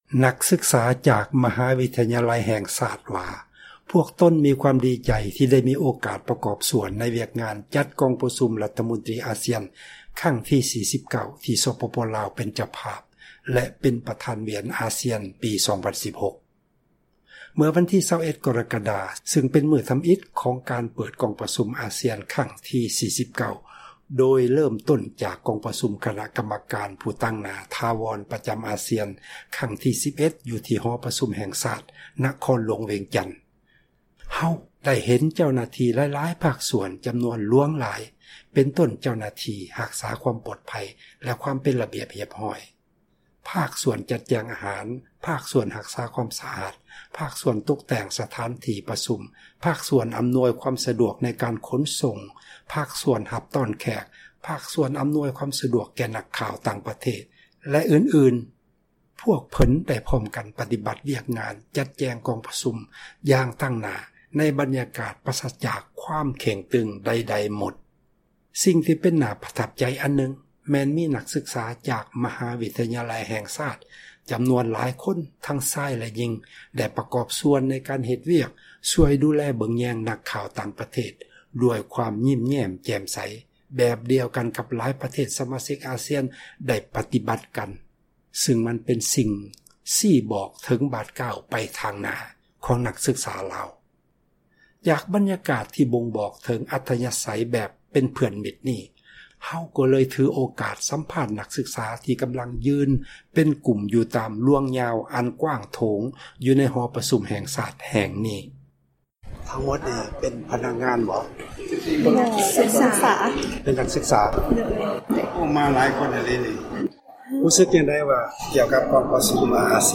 ສໍາພາດນັກສຶກສາ ມະຫາ ວິທຍາໄລ ແຫ່ງຊາດ